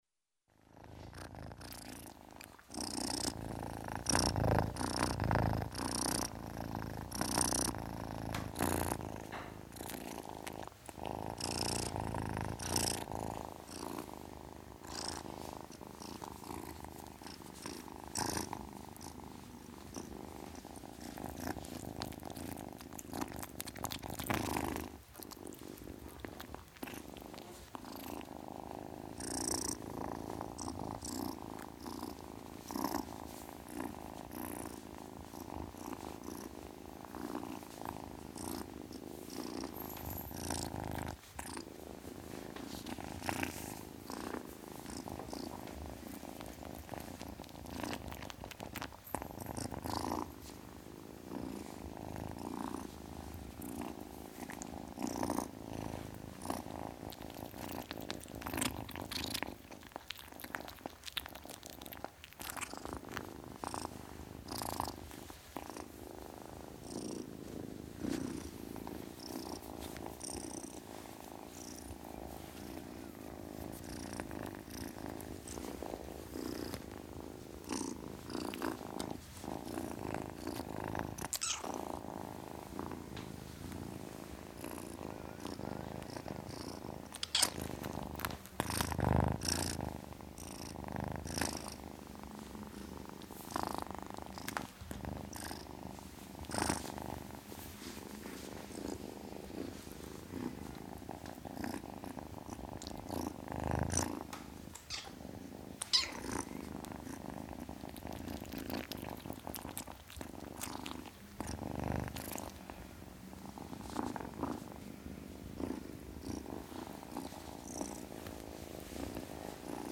Звук нежного урчания